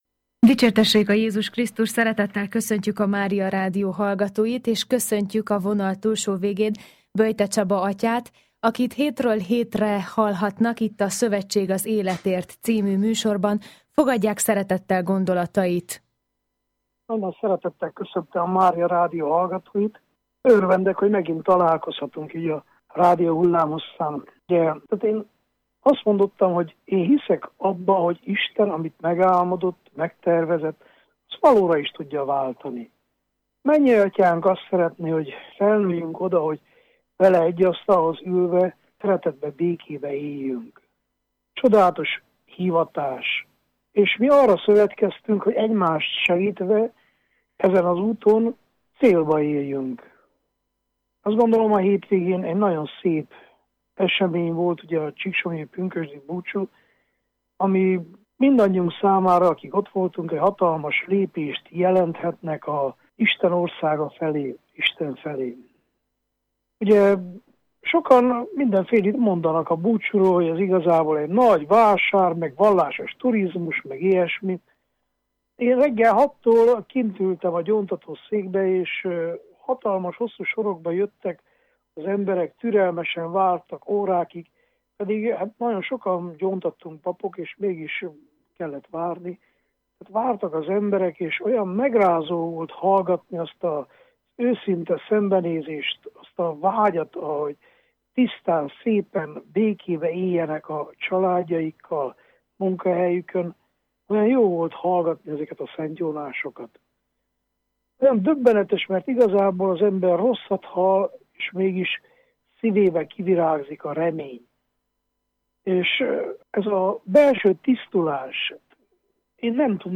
Csaba testvér elmélkedését hallhatta a széles közönség a Mária Rádió, Szövetség az életért című műsorában.